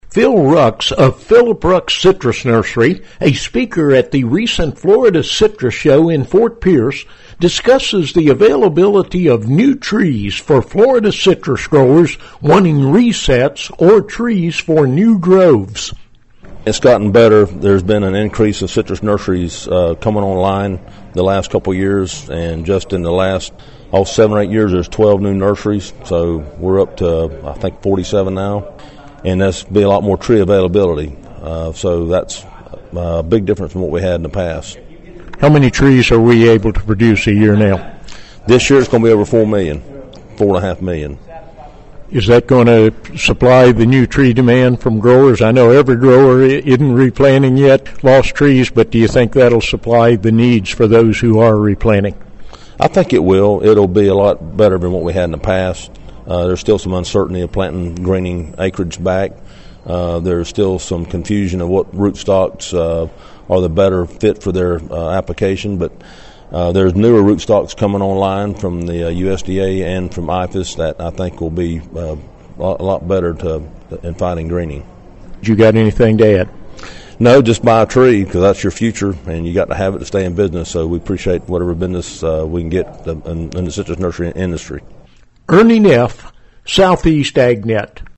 spoke on the topic at the recent Florida Citrus Show in Fort Pierce